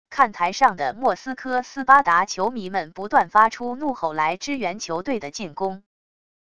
看台上的莫斯科斯巴达球迷们不断发出怒吼来支援球队的进攻wav音频